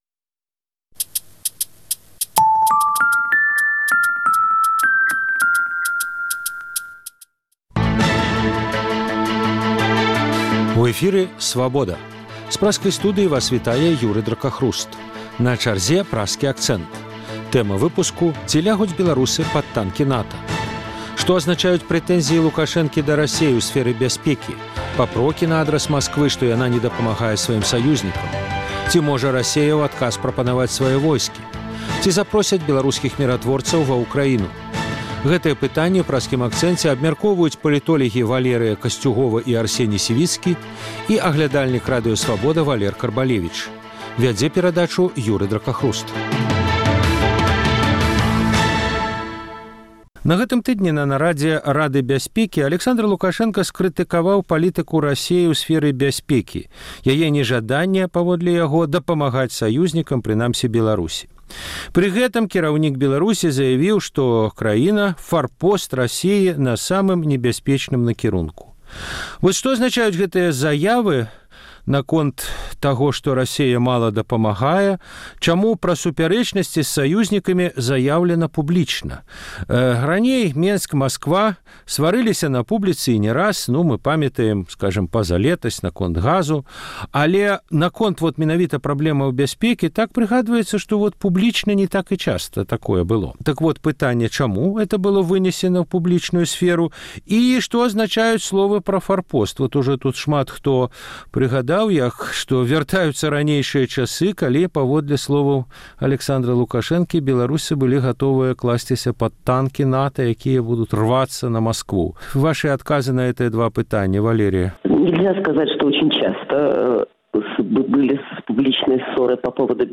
Што азначаюць прэтэнзіі Лукашэнкі да Расеі ў сфэры бясьпекі, папрокі на адрас Масквы, што яна не дапамагае сваім саюзьнікаў? Ці можа Расея ў адказ прапанаваць свае войскі? Ці запросяць беларускіх міратворцаў ва Ўкраіну? Гэтыя пытаньні ў Праскім акцэнце абмяркоўваюць палітолягі